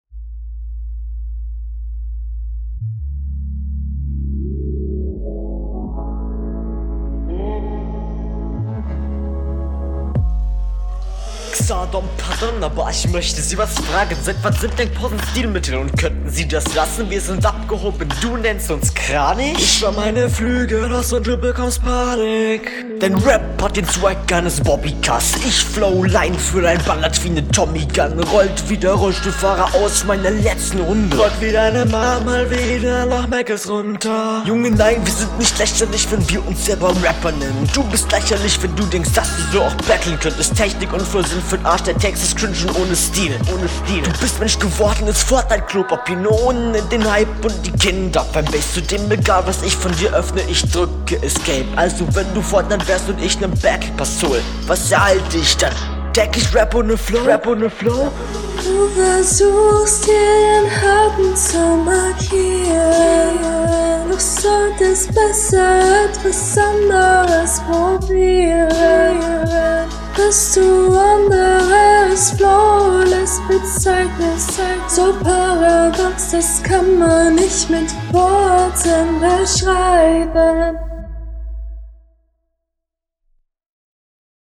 mix ist wieder nicht sehr nice. diesmal ist die stimme konstanter aber alles klingt sehr …
Ey noch druckvoller gerappt nice.
Soundbild Das Autotune ist nicht gut was es wahrscheinlich sollte deswegen geht fit als punch.